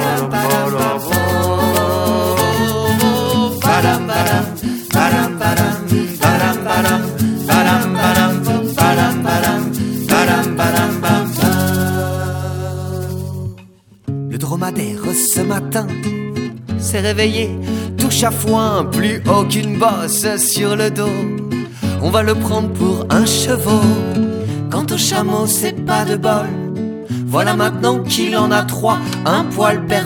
Un trio pour les enfants et leurs parents.